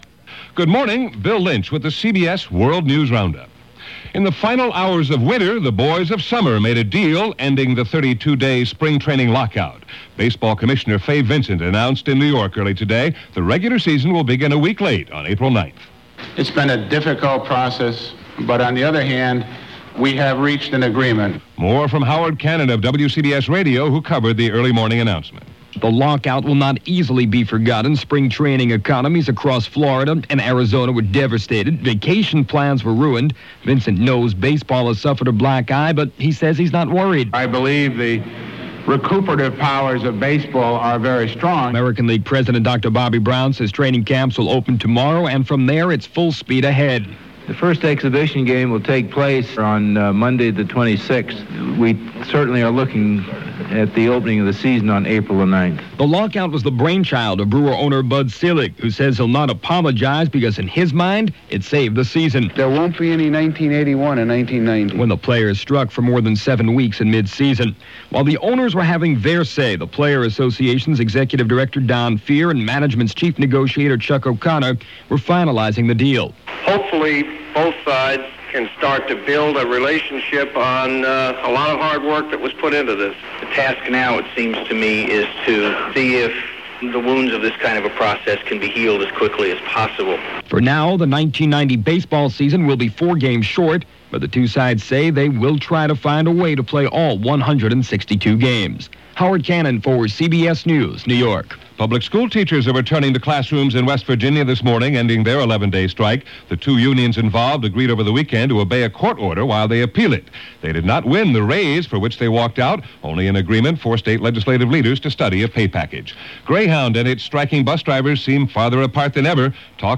And that’s a little of what happened, this March 19, 1990 as presented by The CBS World News Roundup.